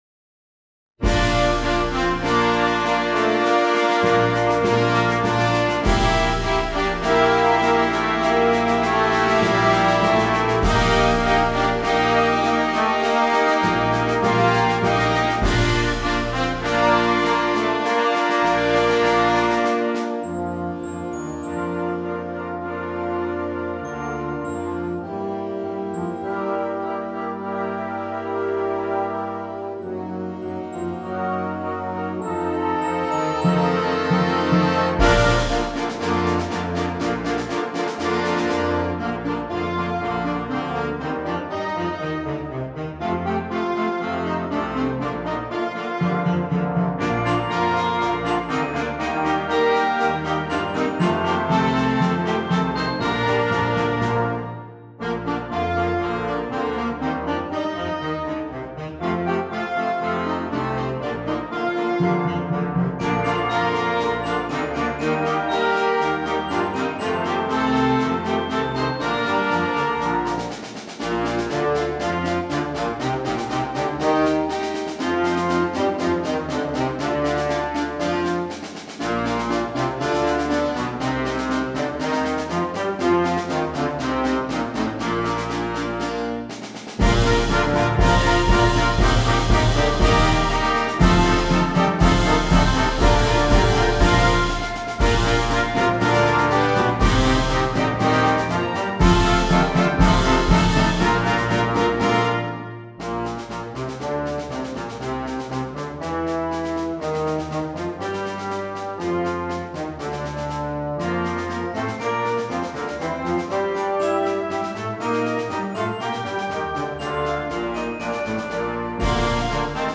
• Flauta
• Oboe
• Saxofón Alto 1
• Trompeta en Bb 1
• Trombón 1
• Tuba
• Glockenspiel
• Timbal